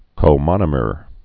(kō-mŏnə-mər)